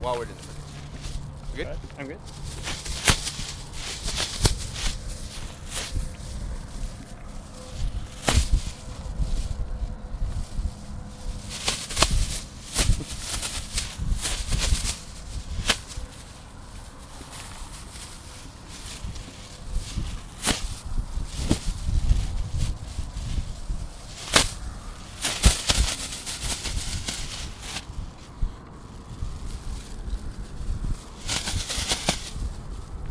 target-bag-in-the-wind.mp3